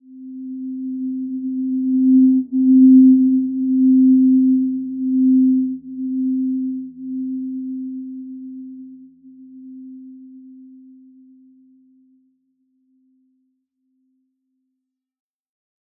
Simple-Glow-C4-p.wav